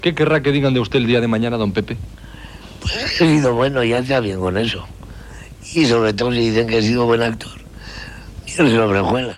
Entrevista a l'actor Pepe Isbert
Extret de Crònica Sentimental de Ràdio Barcelona emesa el dia 22 d'octubre de 1994.